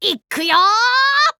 9-Volt's voice from the official Japanese site for WarioWare: Move It!
WWMI_JP_Site_9_Volt_Voice.wav